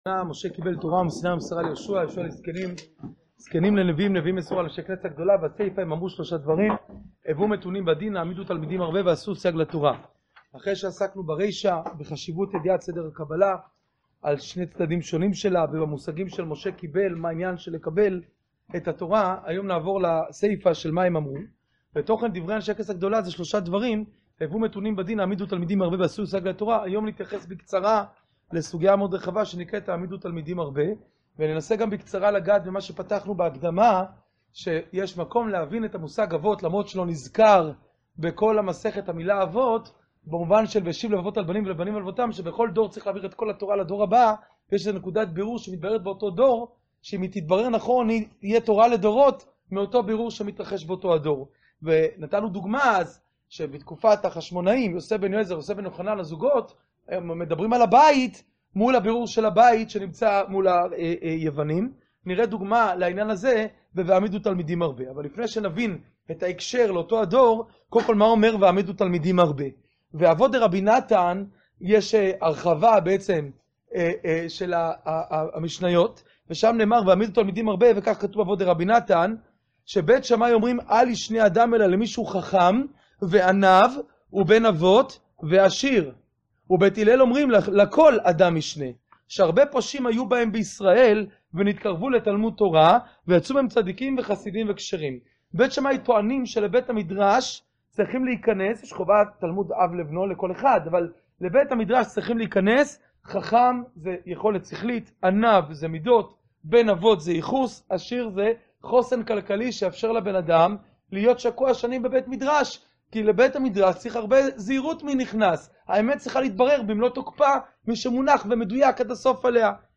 שיעור פרק א משנה א